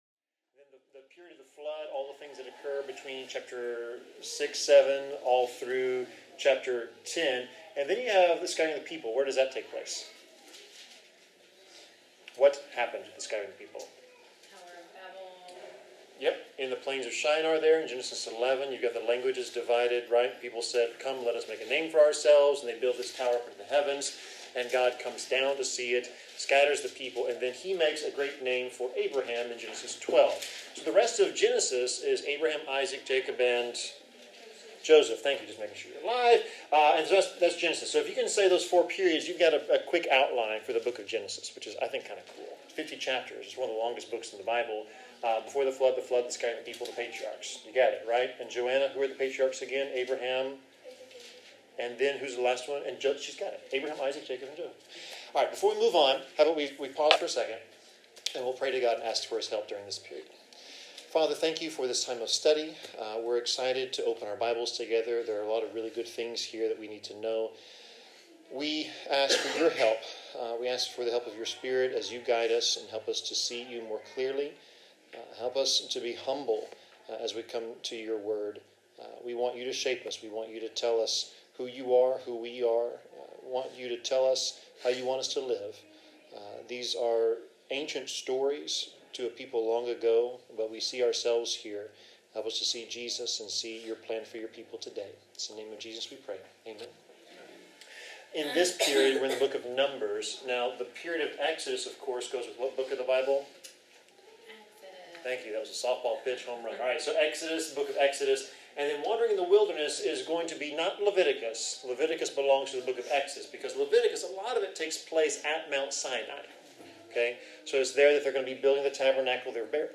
Bible class: Numbers 26-27
Passage: Numbers 26-27 Service Type: Bible Class